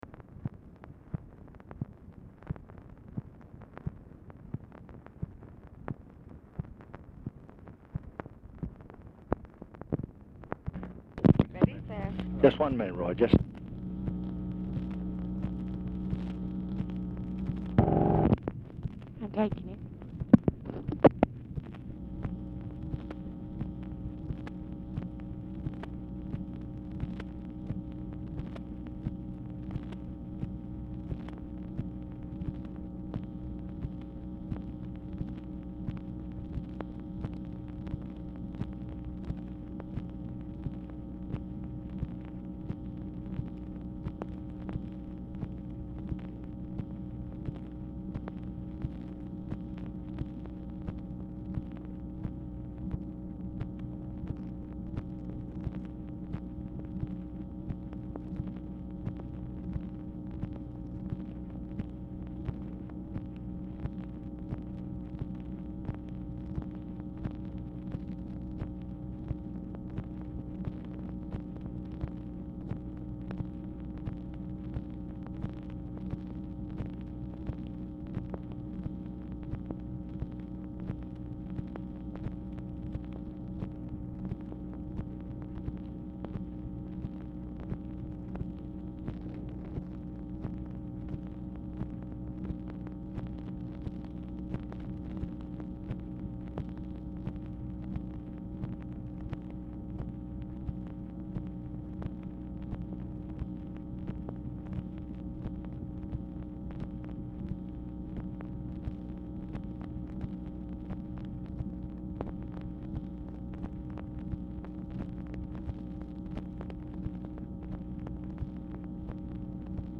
Telephone conversation # 11343, sound recording, LBJ and ROY WILKINS, 1/11/1967, 8:27PM
RECORDING STARTS AFTER CONVERSATION HAS BEGUN
Format Dictation belt